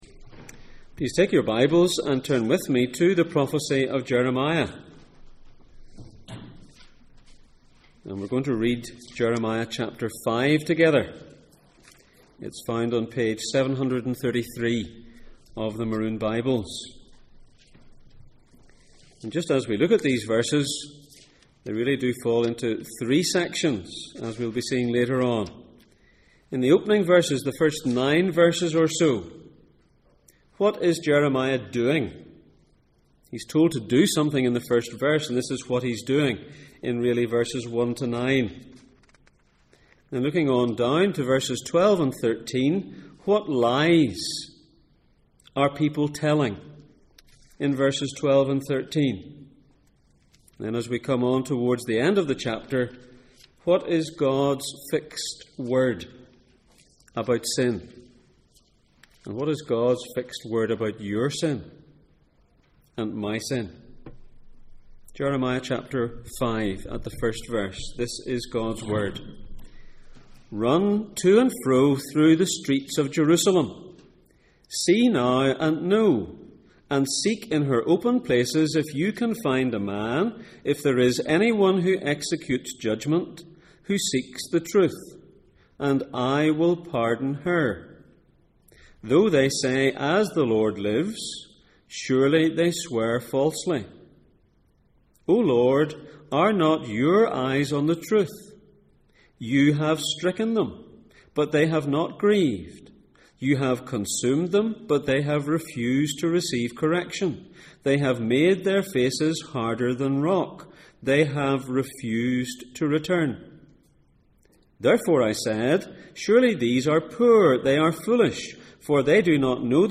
The highs and lows of Jeremiah Passage: Jeremiah 5:1-31, Revelation 5:2-10 Service Type: Sunday Morning %todo_render% « Do you need Radical Repentance?